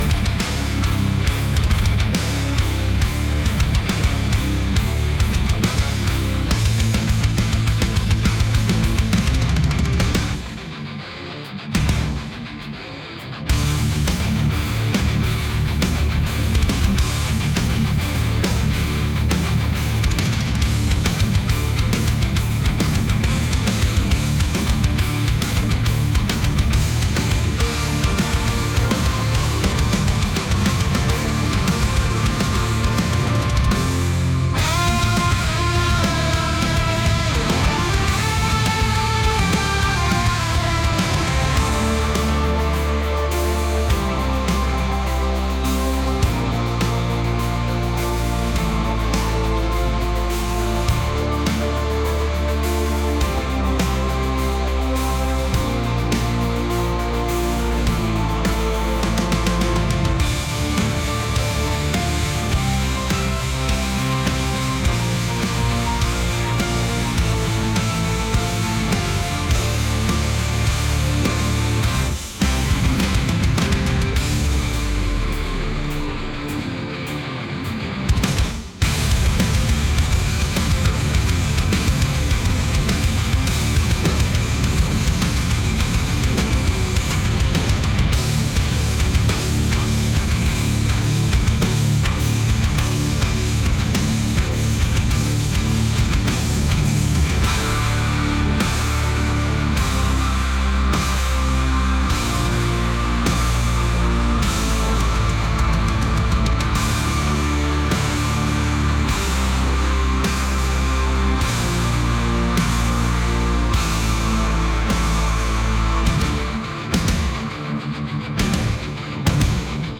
alternative | rock | ambient